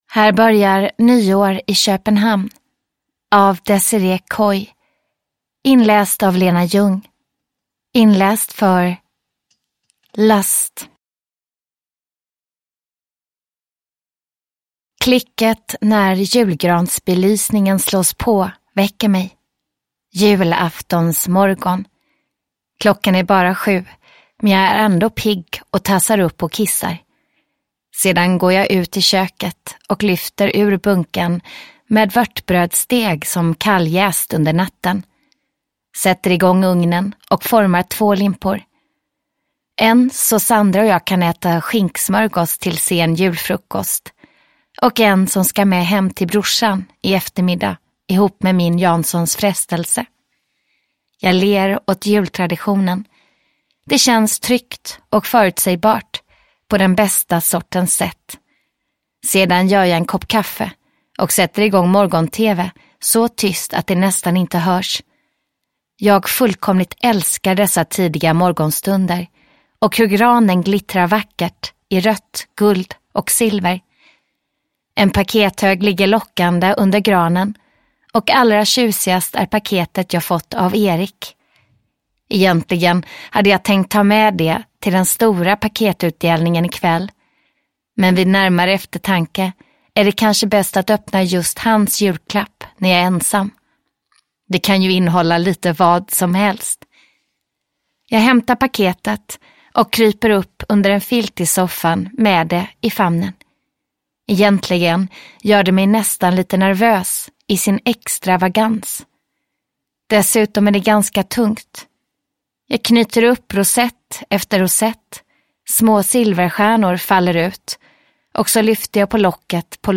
Nyår i Köpenhamn - erotisk romance (ljudbok) av Desirée Coy